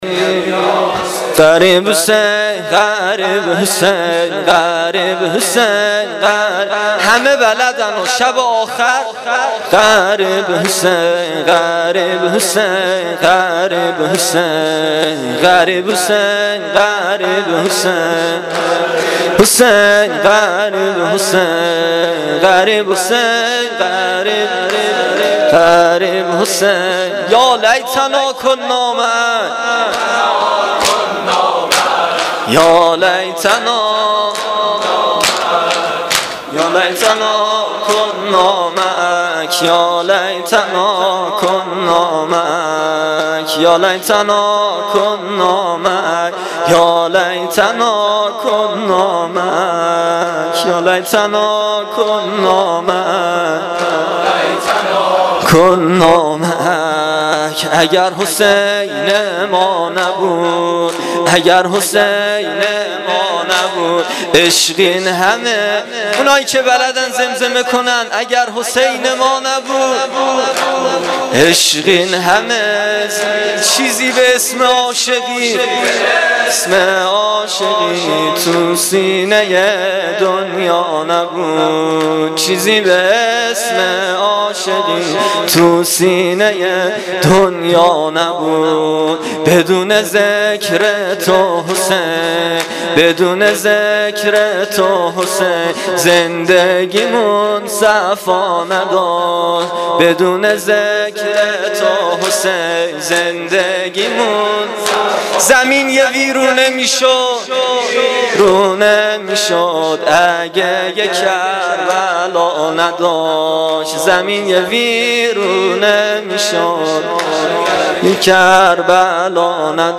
شور و تک - شام غریبان حسینی